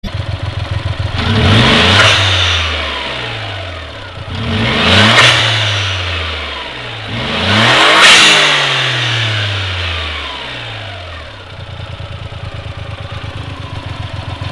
Blow Off Bulb Valve
低過給圧時は「シュ〜」、高過給圧時に「プシュー」（かなりの高い音）という感じで圧が
動作音(mp3)　エンジンルームの音を録音してみました。
フィンは低周波タイプのパープルフィンに交換しています。
笛みたいな音はなくなりました。　全体的には音量は抑えられた感じがします。
sound-c-engine1.MP3